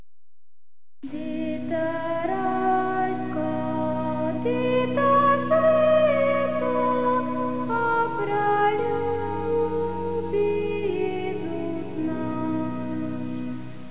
The songs were sung by different choirs as: Ljubljanski oktet, Slovenski komorni zbor, Deseti brat oktet,